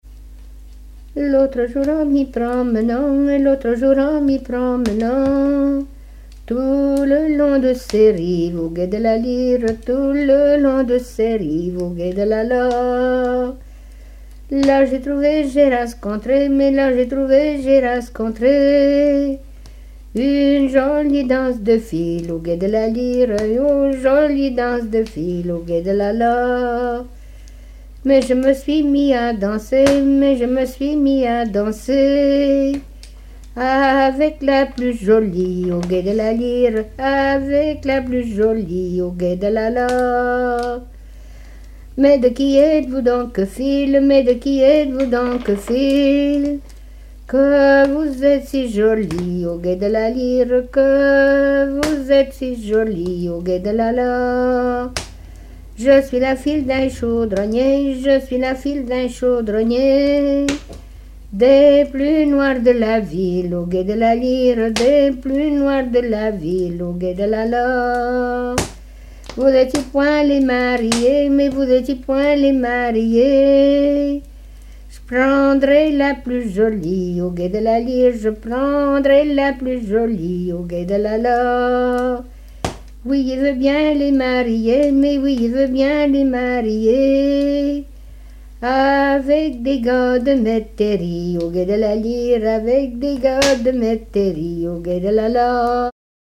danse : ronde : grand'danse
Genre laisse
Pièce musicale inédite